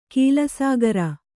♪ kīlasāgara